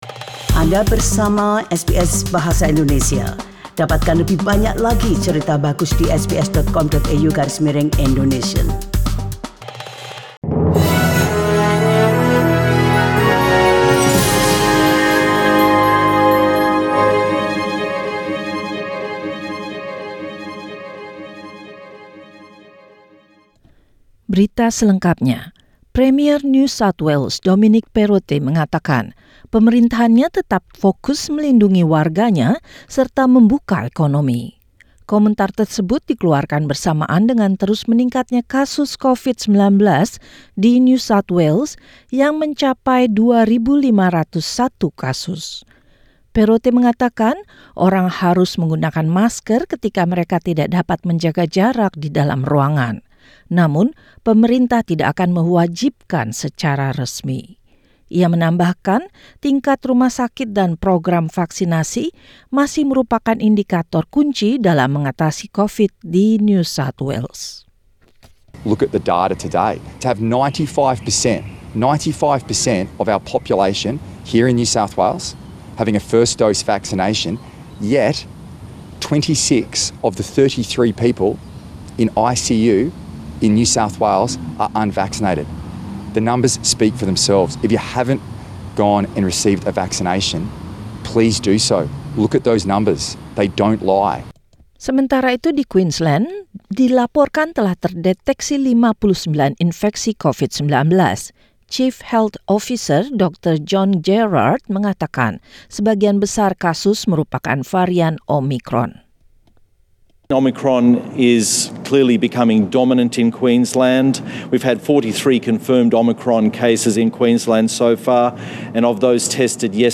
SBS radio news in Indonesian, Monday, 20 December 2021.